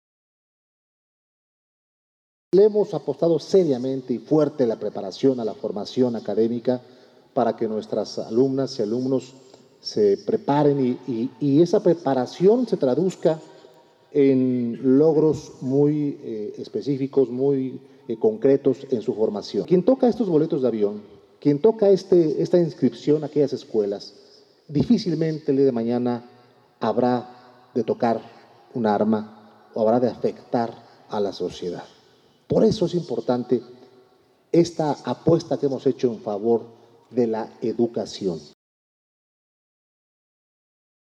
En el Centro de Convenciones de Toluca, el titular del Ejecutivo estatal detalló que estas becas se suman a las más de 10 mil que se han entregado en esta categoría y a través de los programas Proyecta 10 mil y Proyecta 100 mil, entre otras.